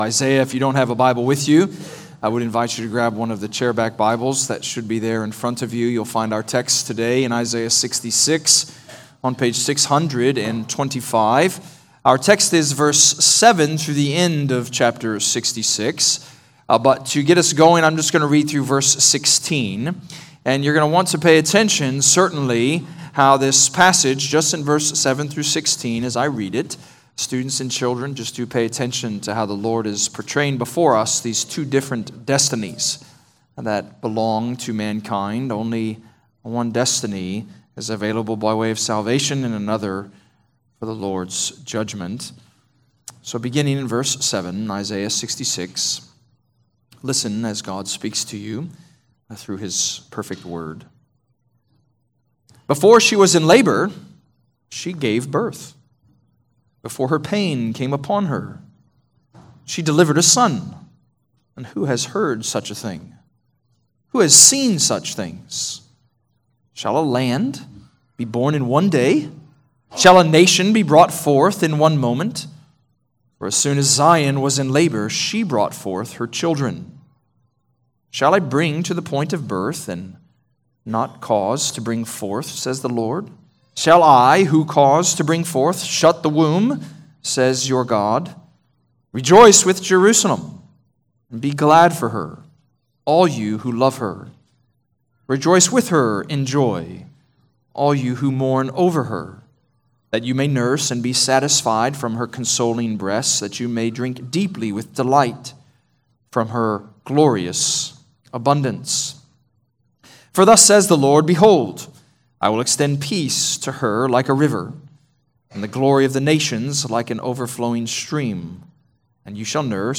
Redeemer Presbyterian Church: Sermon Audio
Download sermons from Redeemer Presbyterian Church in McKinney, TX.